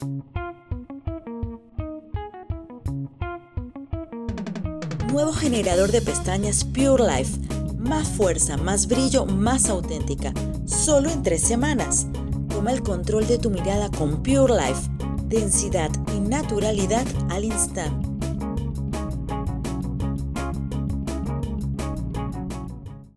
Locutora audaz y versátil
Sprechprobe: Werbung (Muttersprache):
Clear voice with excellent tone and modulation.